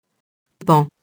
paon [pɑ̃]